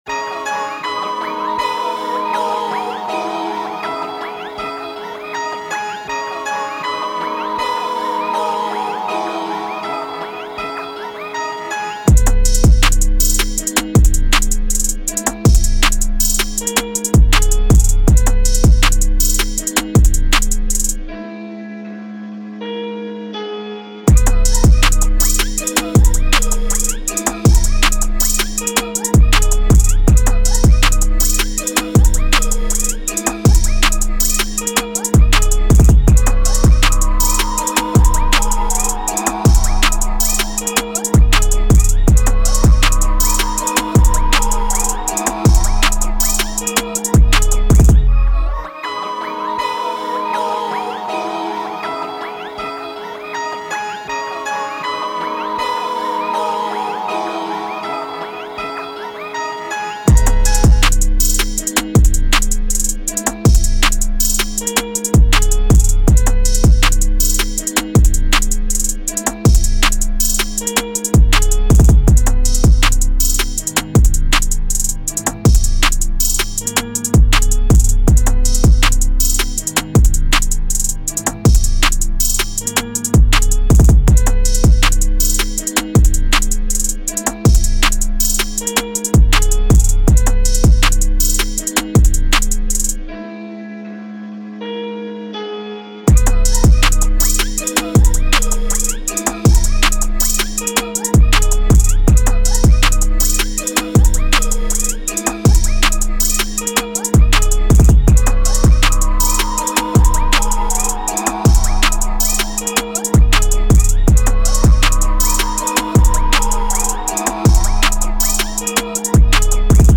Rap Instrumentals